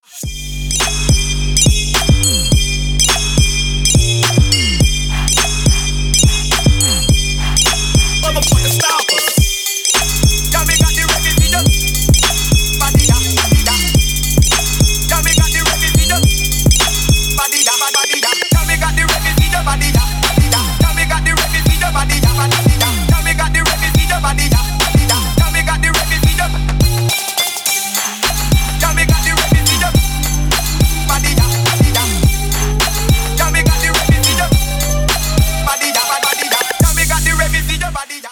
Trap рингтоны